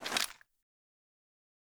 Weapon_Foley 05.wav